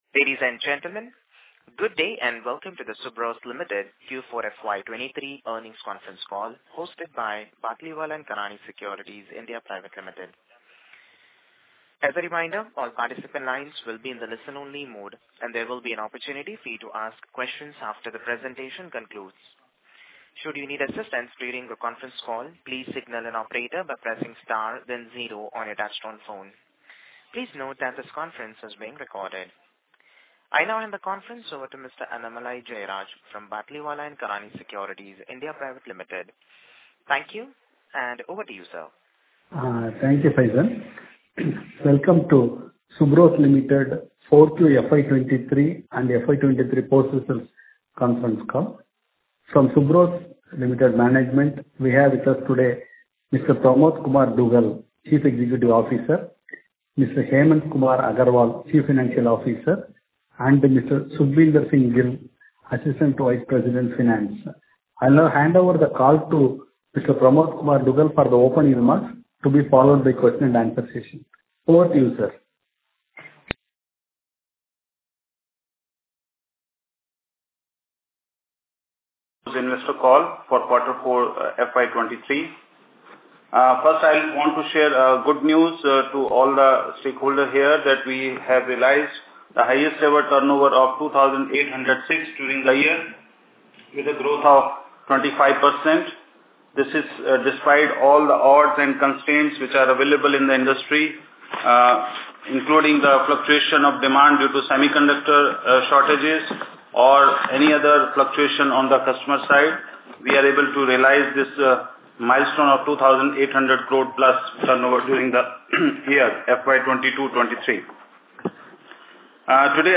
investor call recording Q4.mp3